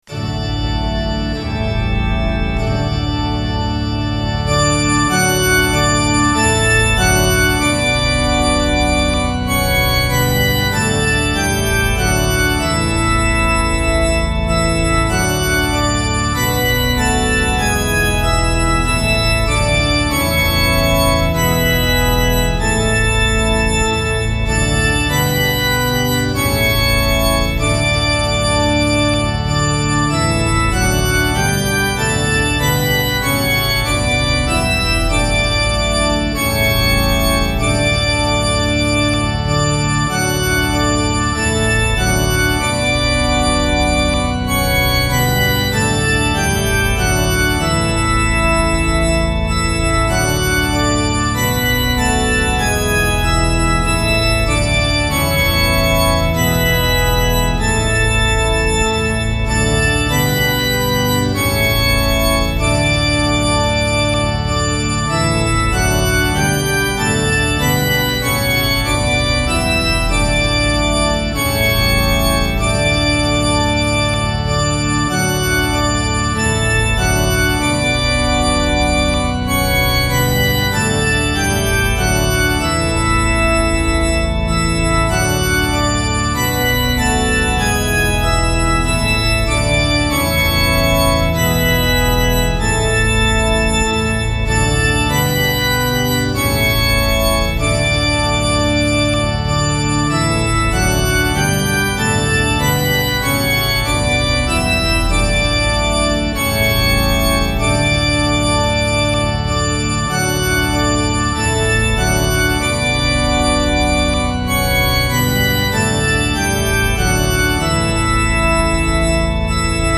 My backing is more fake church organ.